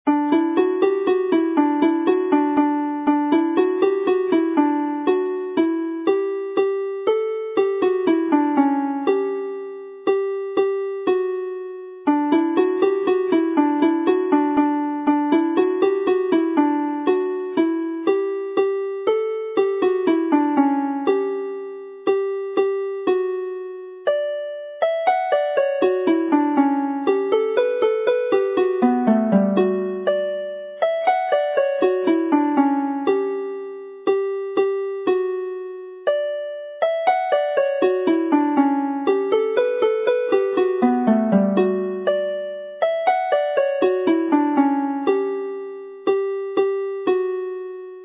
with harmony